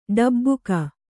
♪ ḍabbuka